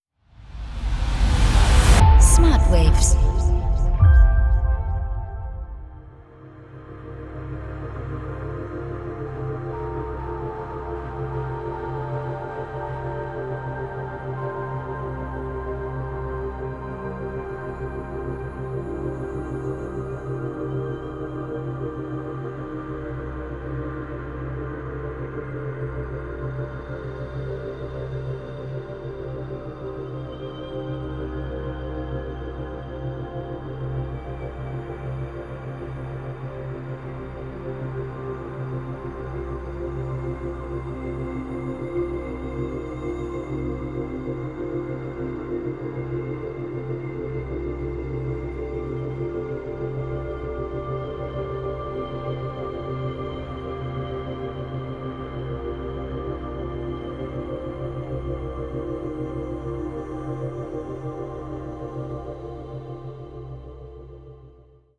Binaurale & Isochrone Beats